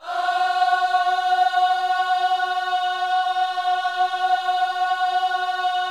OHS F#4A  -R.wav